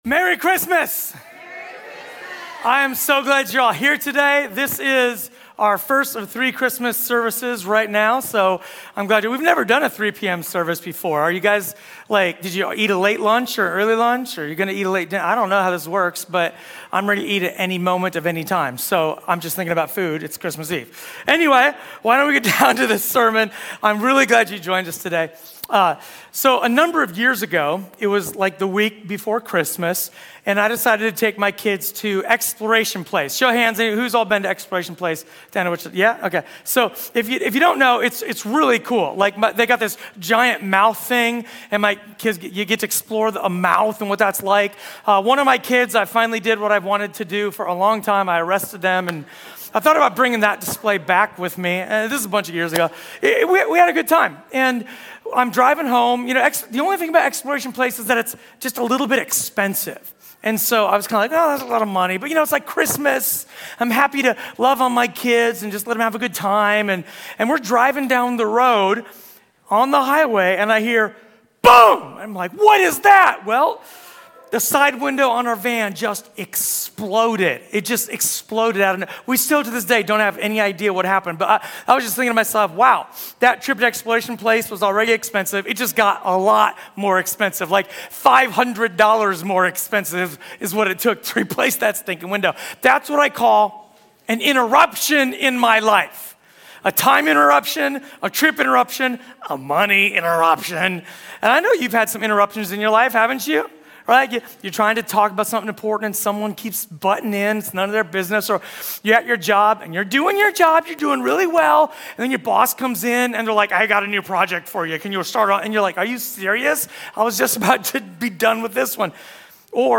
A sermon from the series "Christmas."